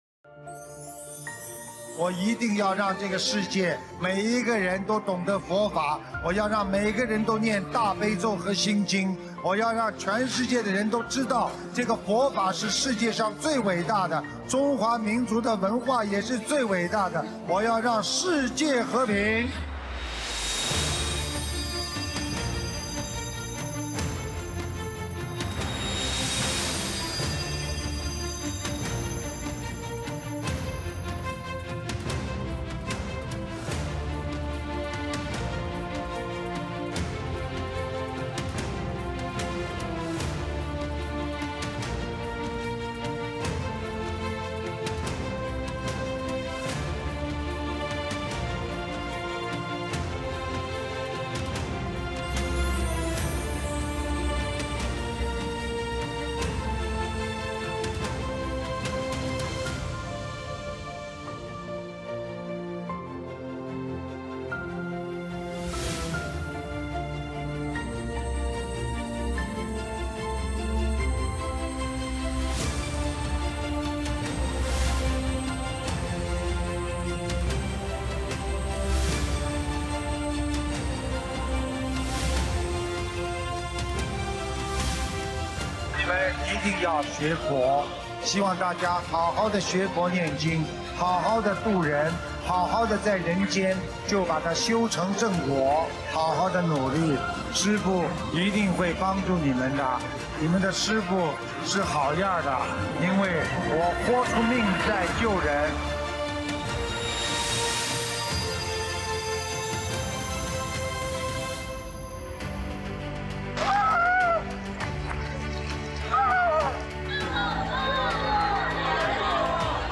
视频：21.佛學會訪談【訪談分享】04 - 新闻报道 慈心善语
首页 >>弘法视频 >> 新闻报道